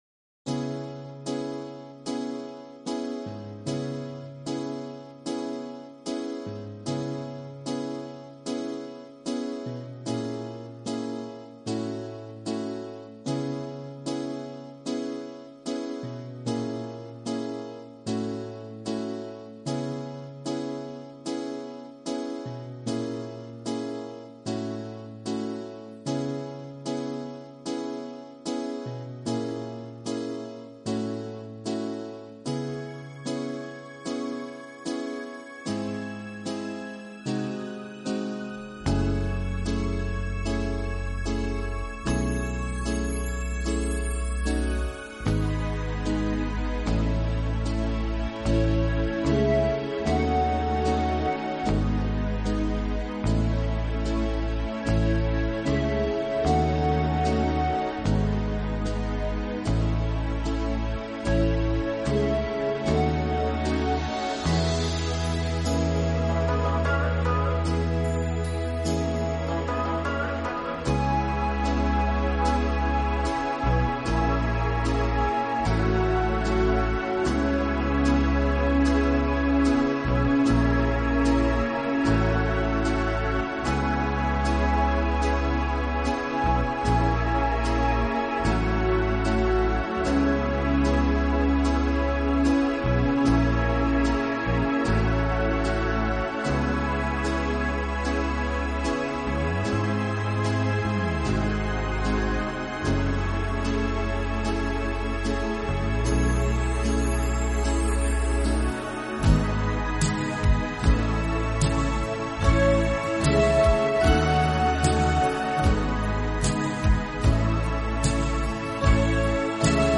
I tried to listen to the poem but all I heard was music on my end did I miss something ?